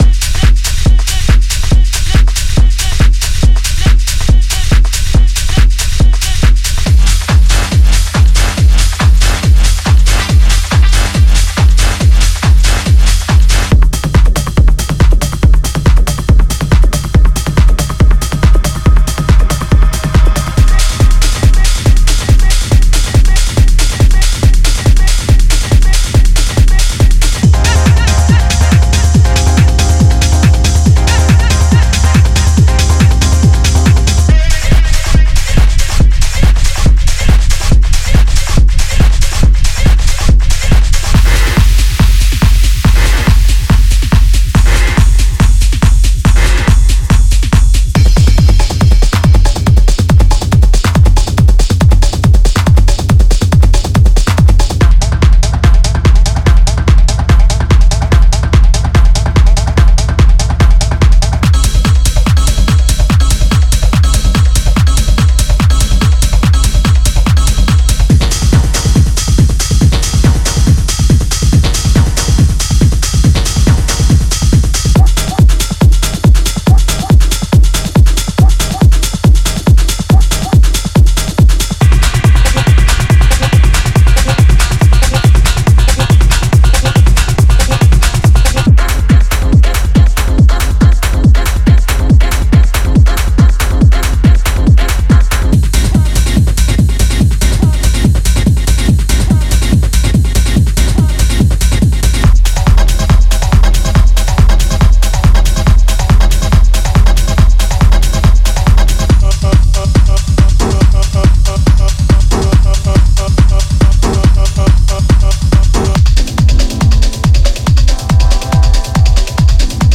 Genre:Techno
HardGroove Techno
デモサウンドはコチラ↓